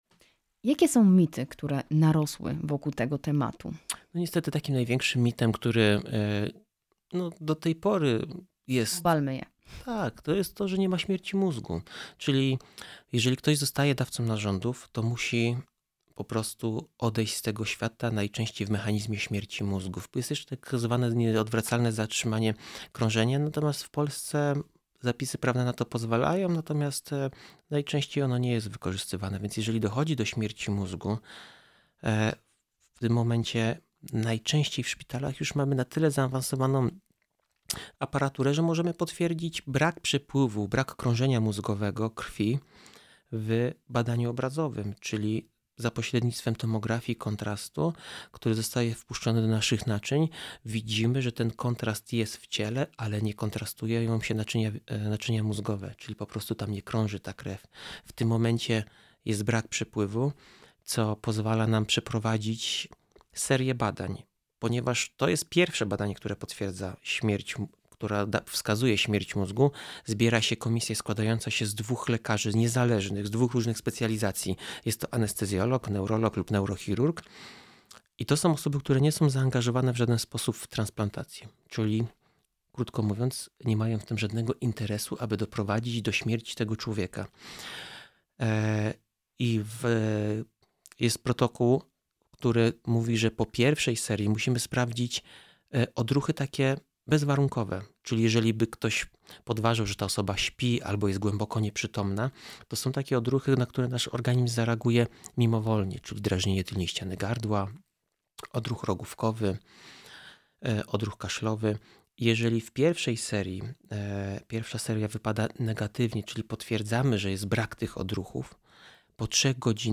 02-rozmowa-zgoda-to-dar-zycia.mp3